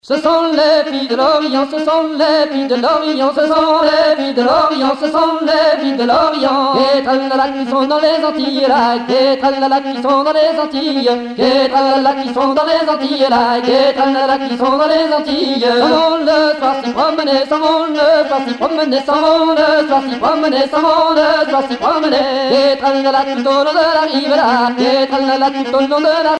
danse : laridé, ridée
Pièce musicale éditée